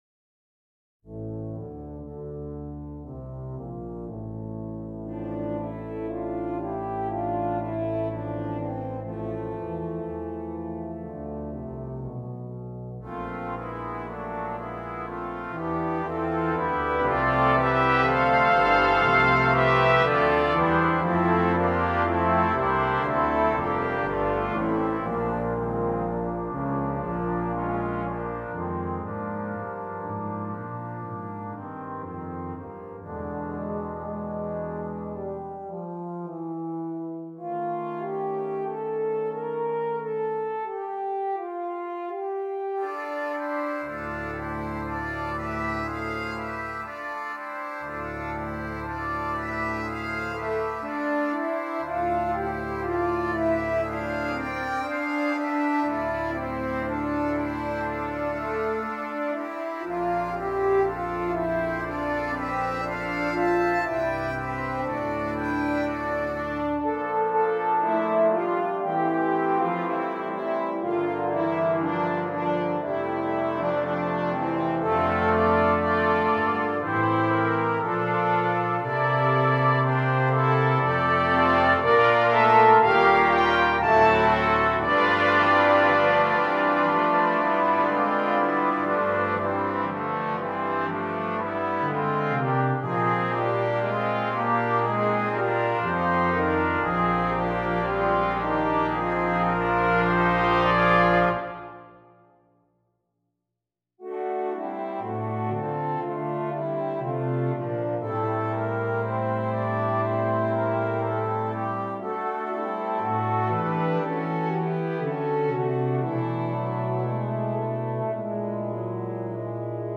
Brass Choir (3.4.3.1.1)
Traditional